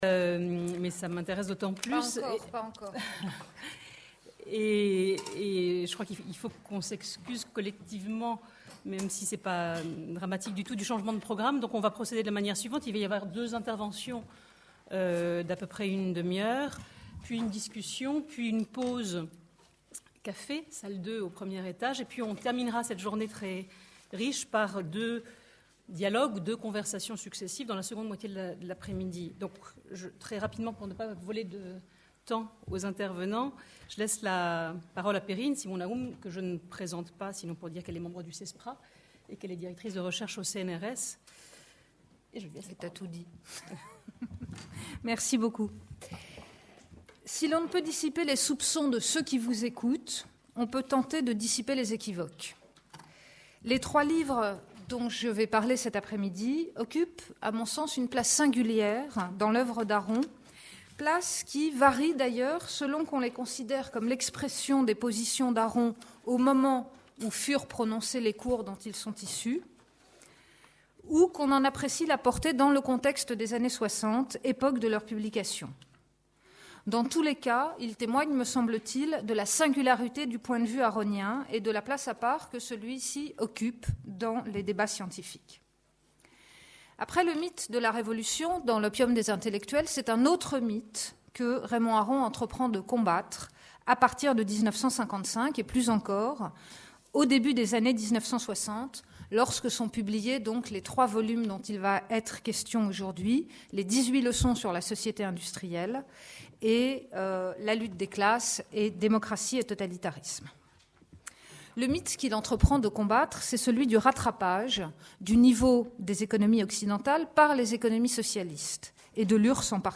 Démocratie : histoire, combats, critiques. 3e table ronde : La démocratie, ses démons et ses ennemis | Canal U